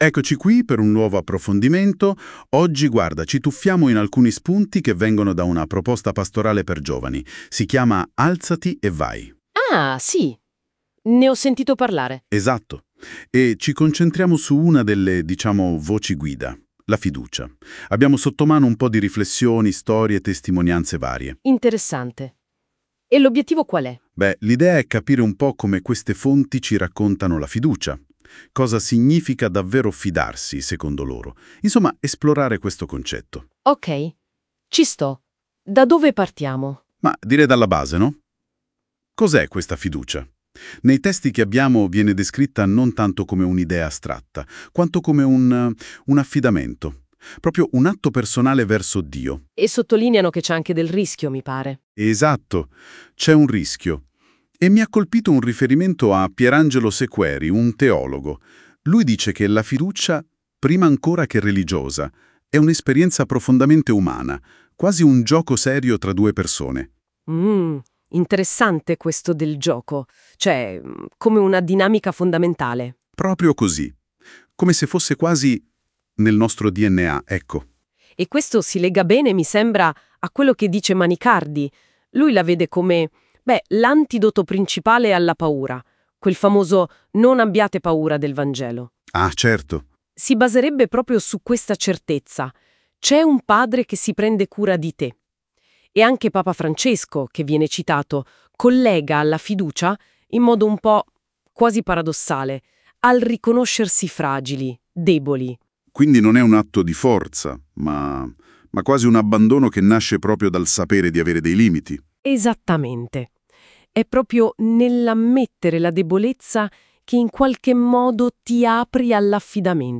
Essi - sulla base del materiale presentato - elaborano i contenuti stessi in forma accattivante: podcast e video sono elaborati dall'AI "NOTEBOOKLM".